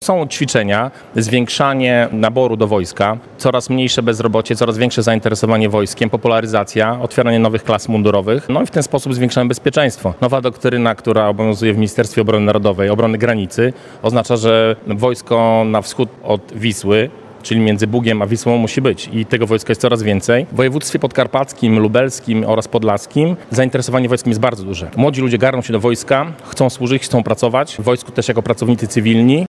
Konferencja w Białej Podlaskiej